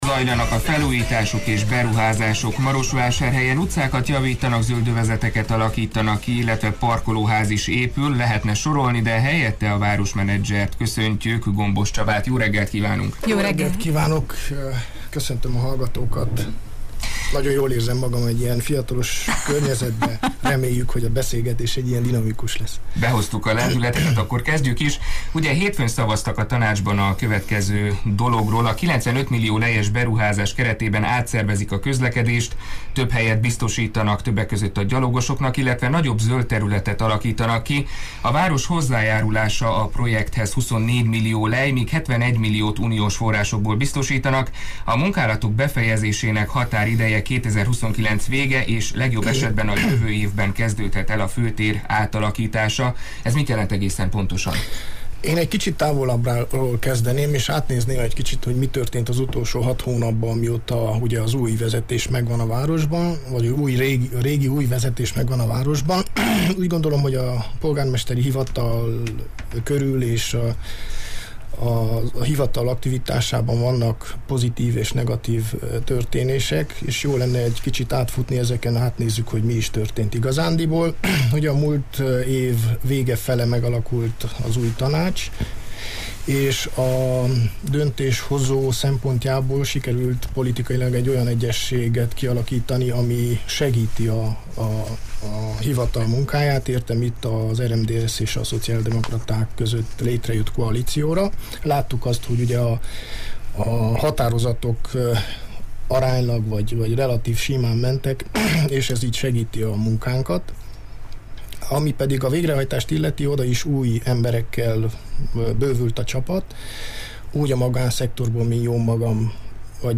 Ma reggel Gombos Csaba városmenedzsert láttuk vendégül, akit – többek közt – ezekről a témákról faggattunk.